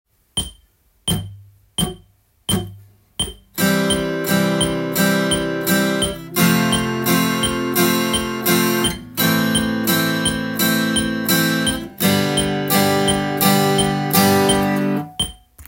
譜面通り弾いてみました
①では全てのコードストロークが必ず裏拍になるように